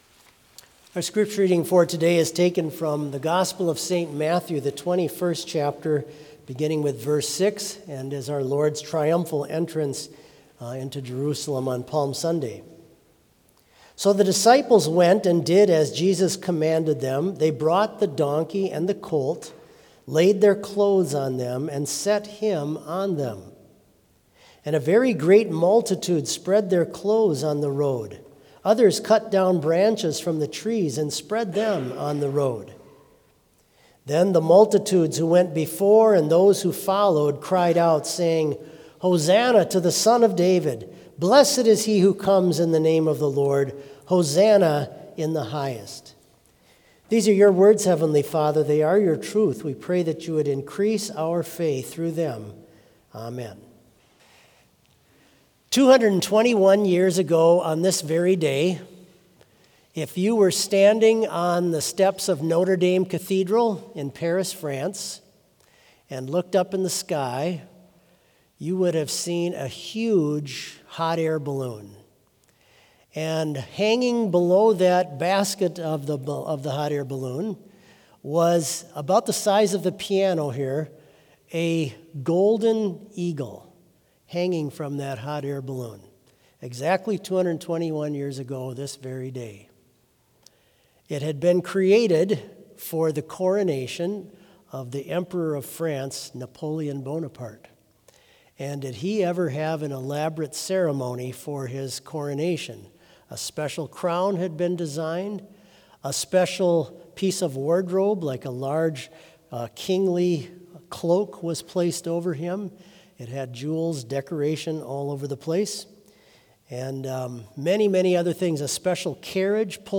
Complete service audio for Chapel - Tuesday, December 2, 2025